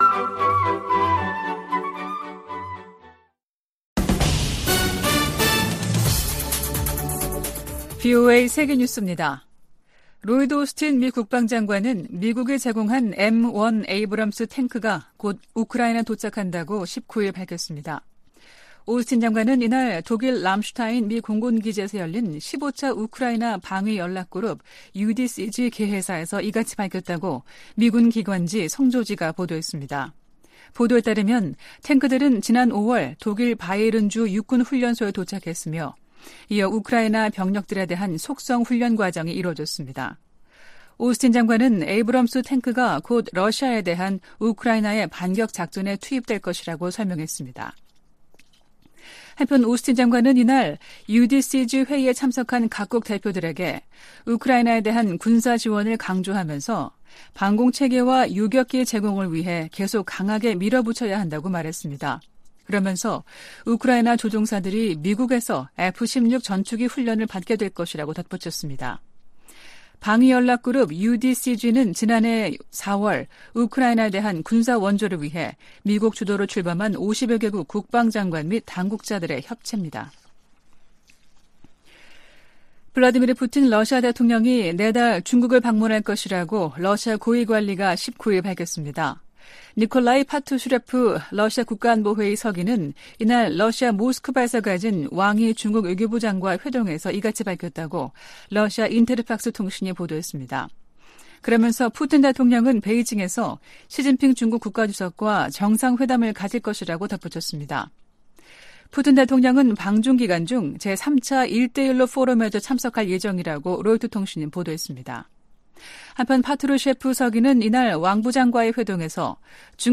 VOA 한국어 아침 뉴스 프로그램 '워싱턴 뉴스 광장' 2023년 9월 20일 방송입니다. 존 커비 백악관 국가안전보장회의(NSC) 전략소통조정관은 북한과 러시아가 무기거래를 할 경우 유엔 회원국과 대응책을 모색할 것이라고 밝혔습니다. 러시아가 북한 김정은 국무위원장에 무인기를 선물한 데 대해 미국 정부가 제재 부과 의지를 밝혔습니다. 북한과 중국, 러시아가 현재 3각 연대를 형성하고 있는 것은 아니라고 필립 골드버그 주한 미국 대사가 말했습니다.